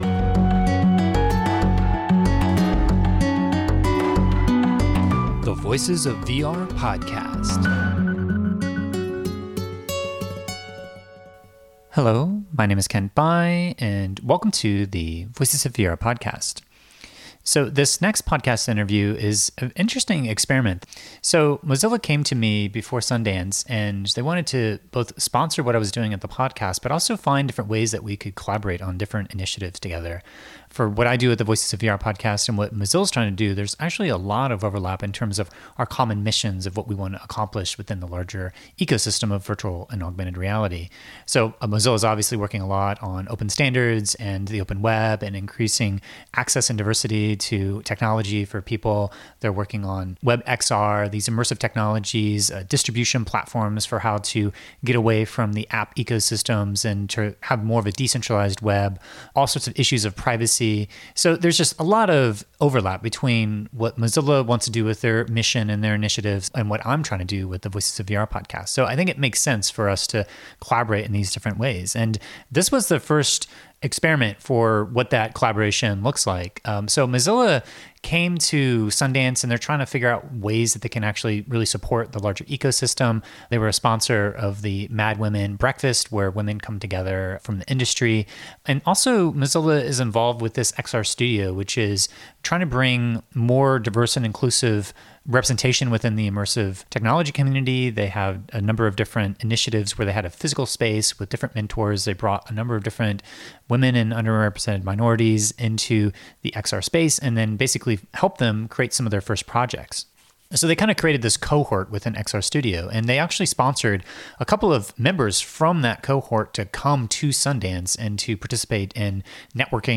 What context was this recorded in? Mozilla curated these seven participants and coordinated the logistics for this conversation to happen during Sundance, but it was up to me decide what to talk about and how to facilitate the overall conversation.